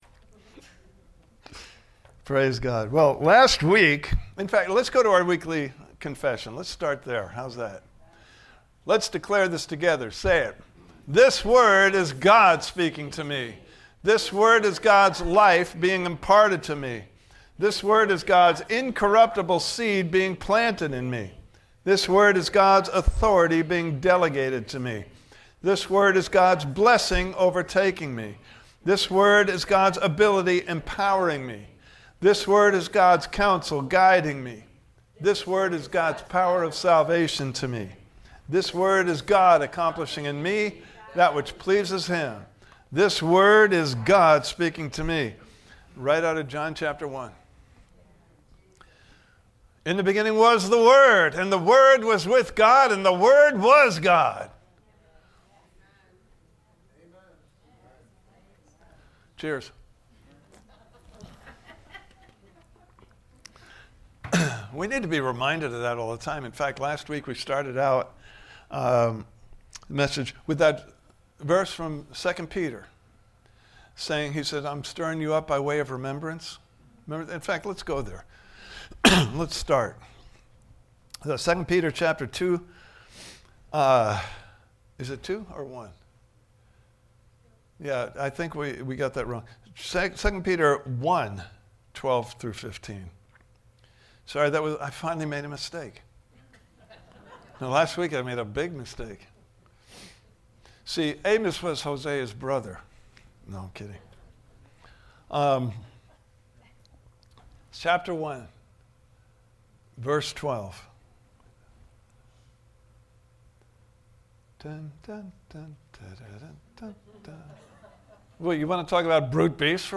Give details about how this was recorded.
Living a Redeemed Life Service Type: Sunday Morning Service « Part 1